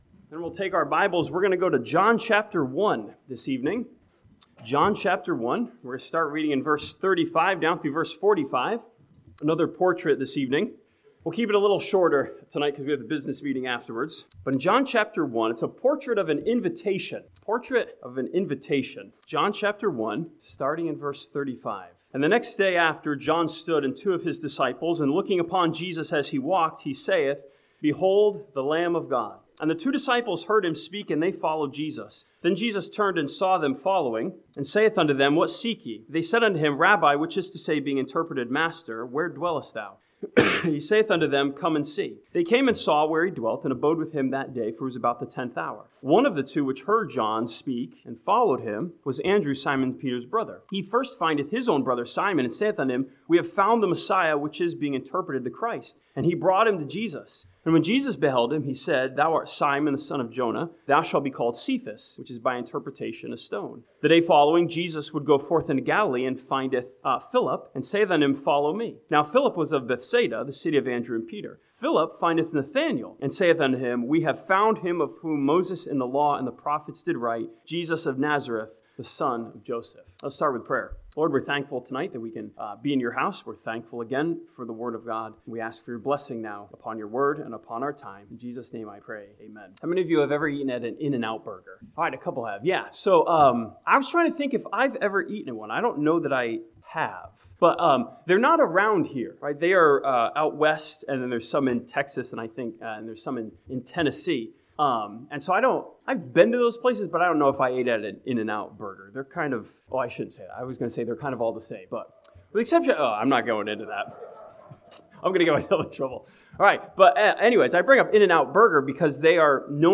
This sermon from John chapter 1 challenges us with a portrait of an invitation to invite people to Jesus.